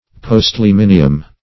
Postliminium \Post`li*min"i*um\, Postliminy \Post*lim"i*ny\, n.